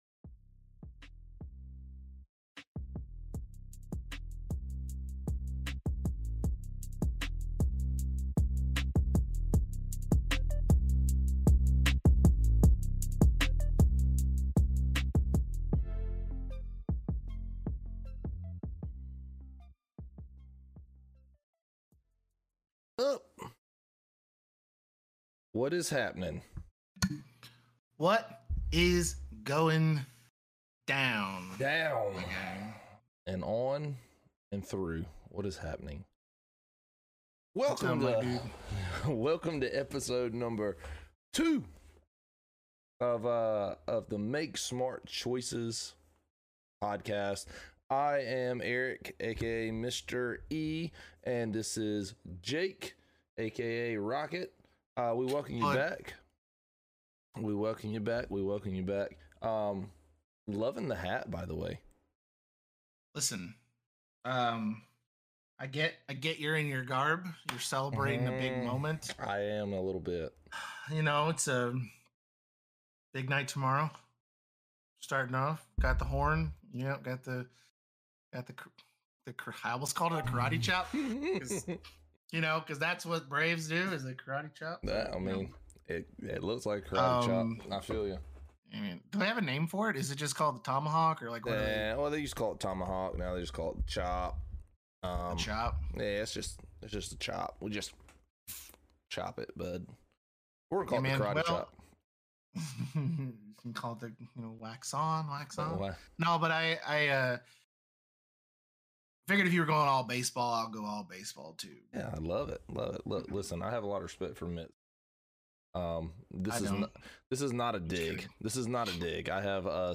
Play Rate Listened List Bookmark Get this podcast via API From The Podcast Two average guys tell personal stories on life's biggest issues and what they learned from the mistakes they've made.